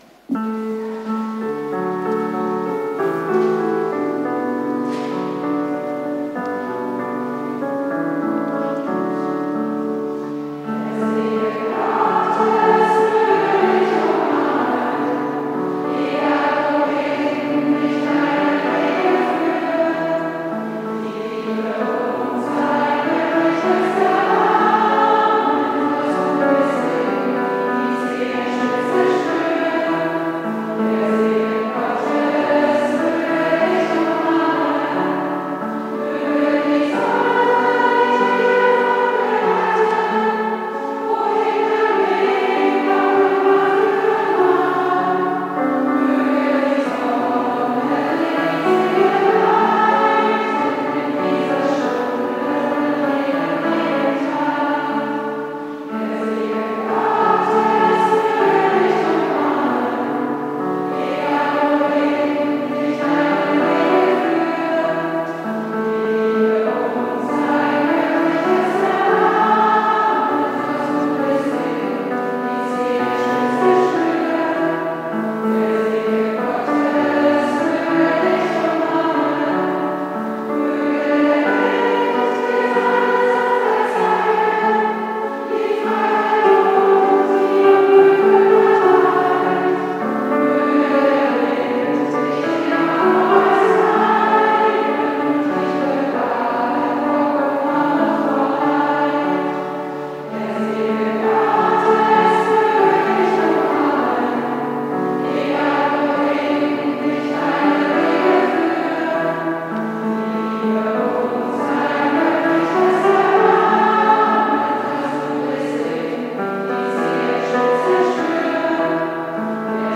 Elternchor: "Der Segen Gottes möge dich umarmen"